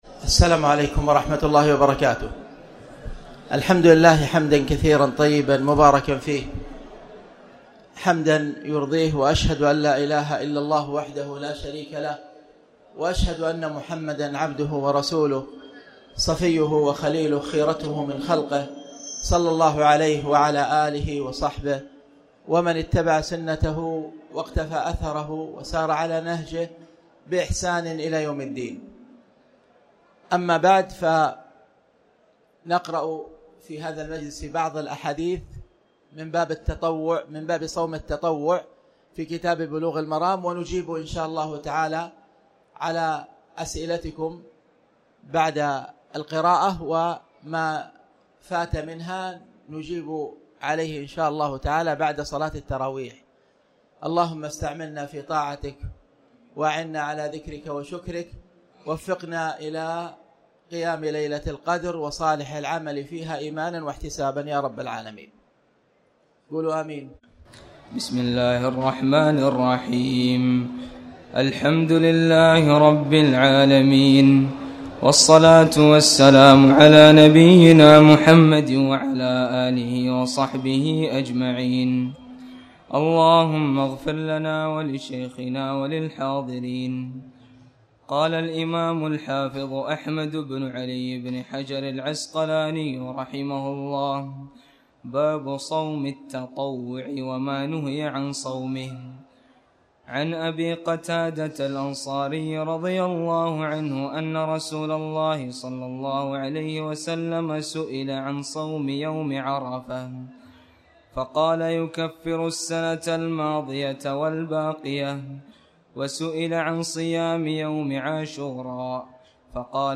تاريخ النشر ٢٢ رمضان ١٤٣٩ هـ المكان: المسجد الحرام الشيخ